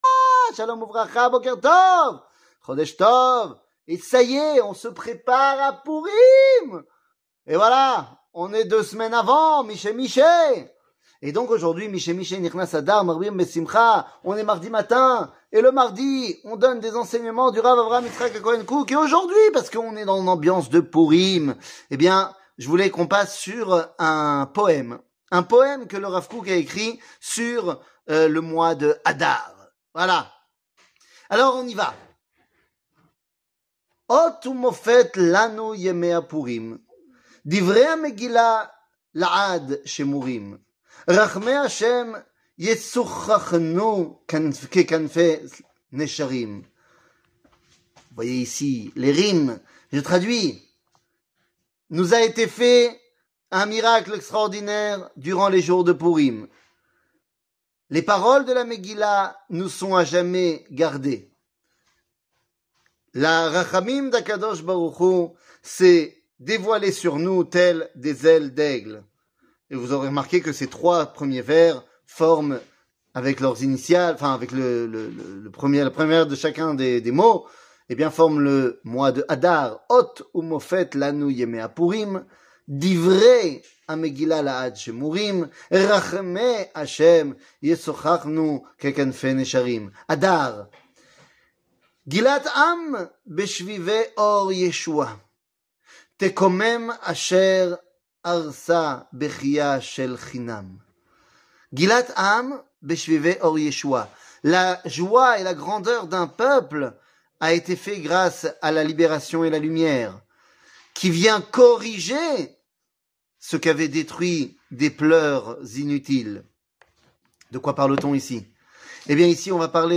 שיעור מ 21 פברואר 2023